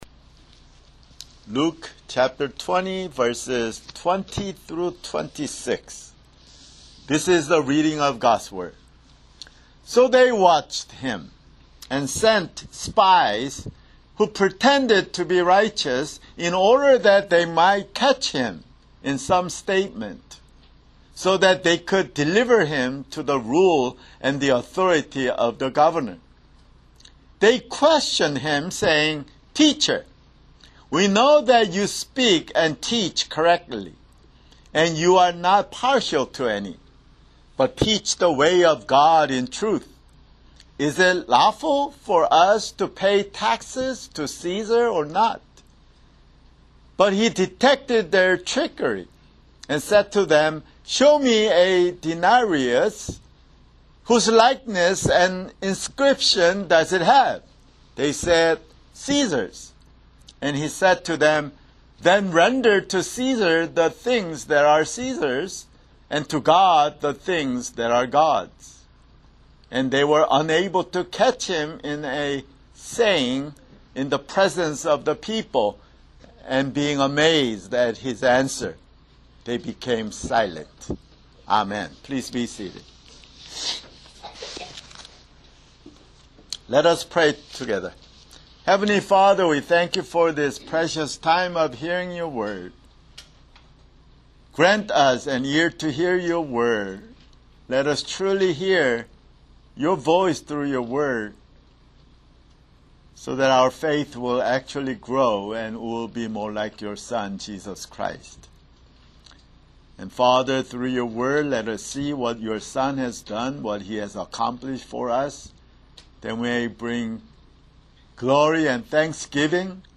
[Sermon] Luke (132)